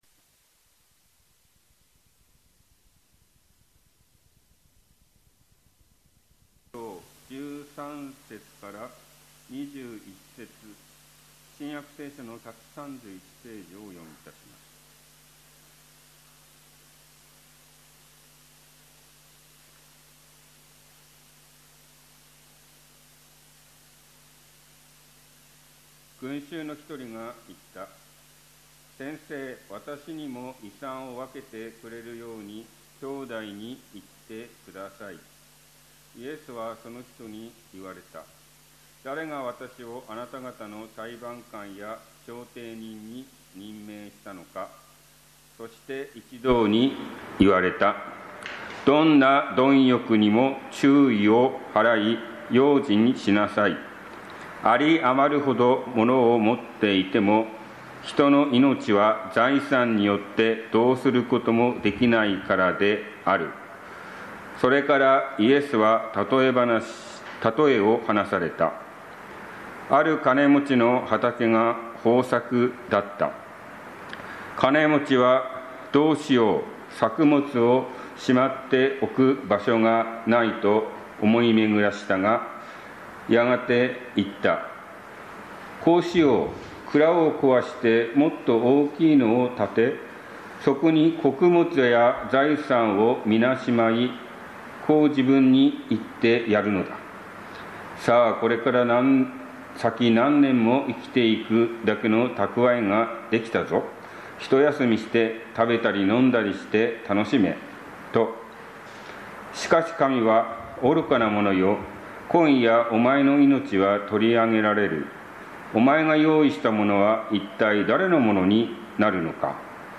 天に宝を積む者となるように 宇都宮教会 礼拝説教